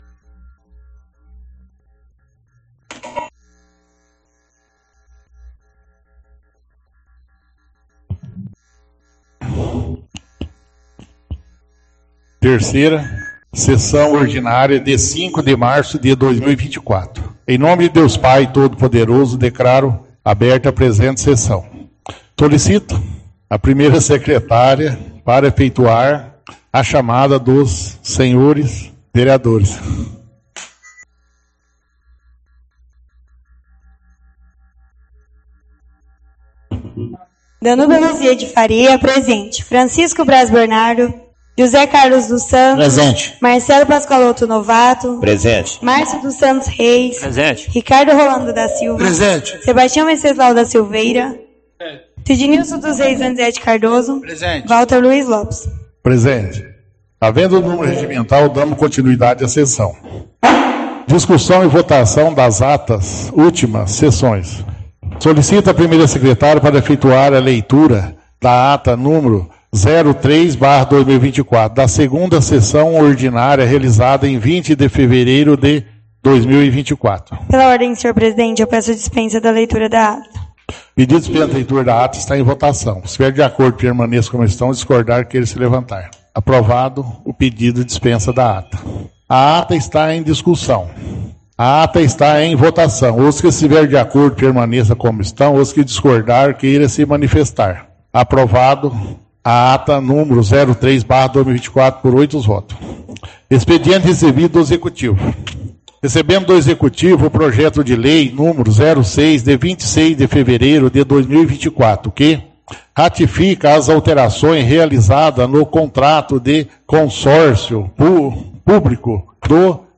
Áudio 3ª Sessão Ordinária – 05/03/2024